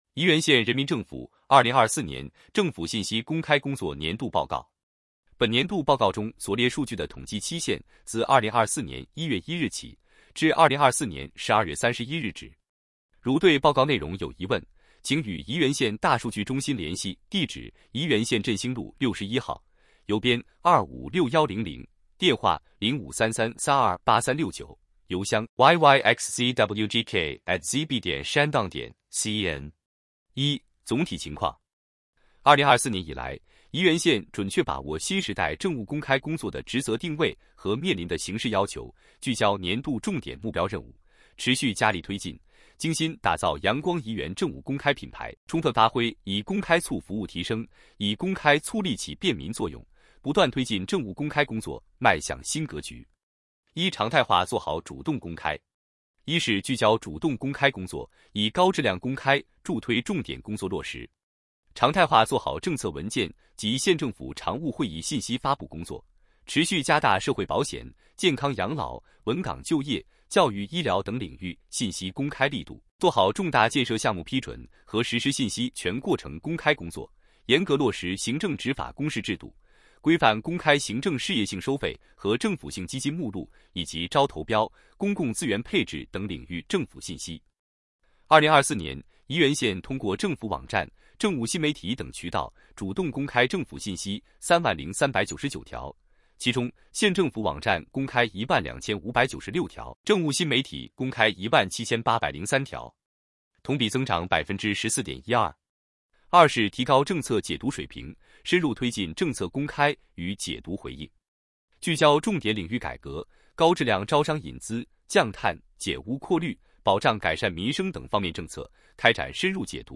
语音播报 | 电子书 | 图片解读 | H5展示 | 本年度报告中所列数据的统计期限自2024年1月1日起，至2024年12月31日止。